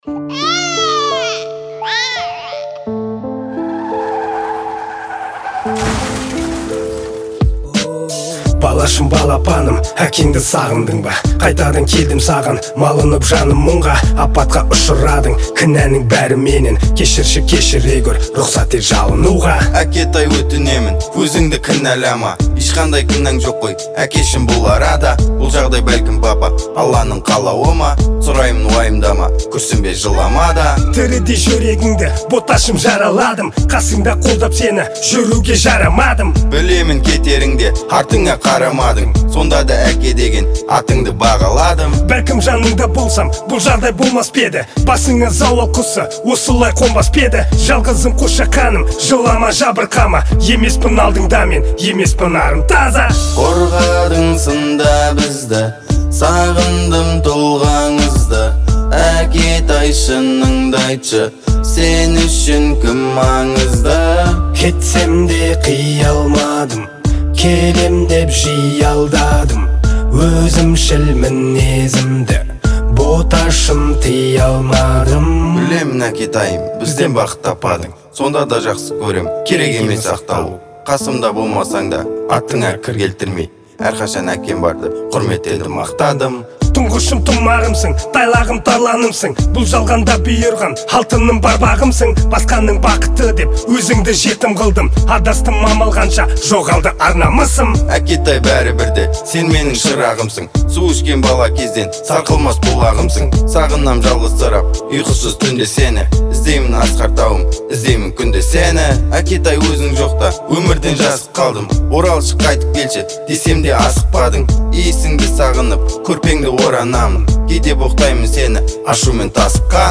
• Жанр: Казахские песни